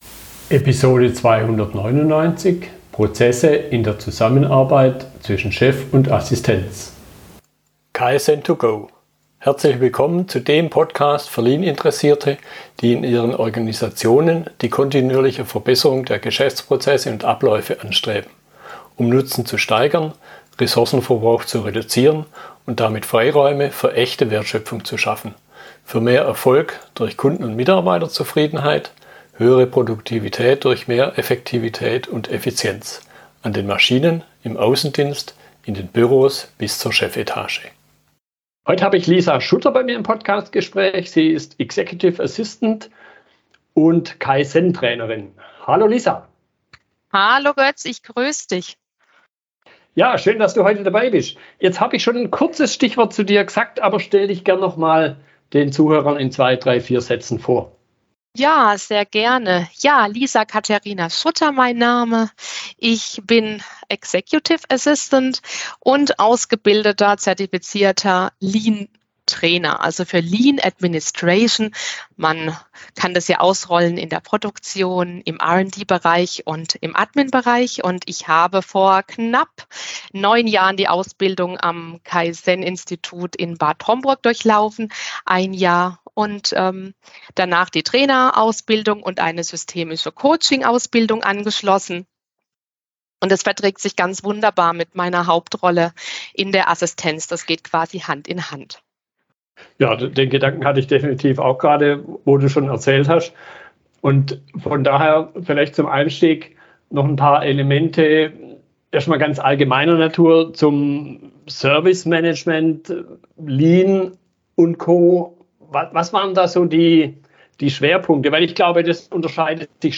Fragestellungen aus der Unterhaltung